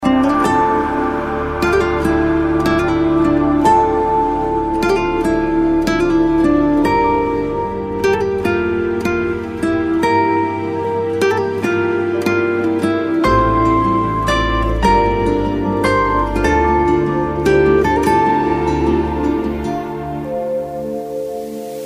آهنگ موبایل عاشقانه و ملایم (بی کلام)